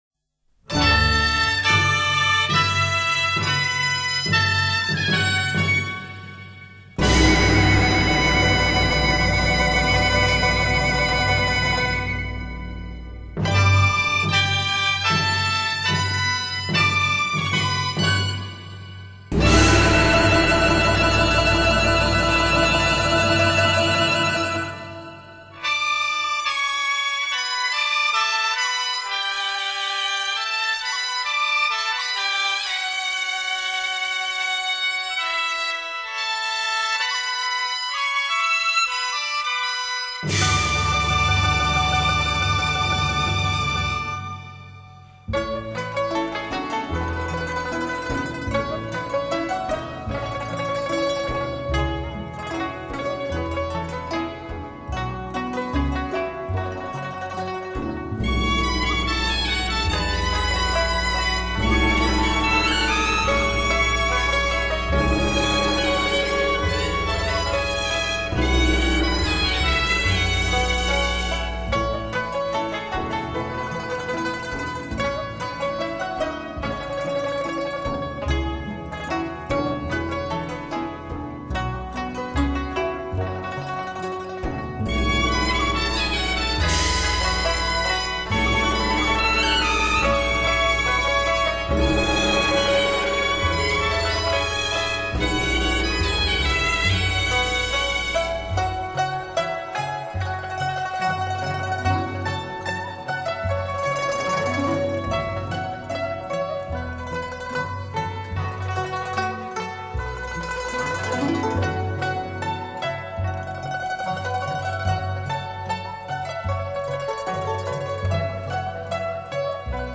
全曲作曲极具雄壮而又含着温柔的气氛，描写这脍炙人口的故事生动逼真。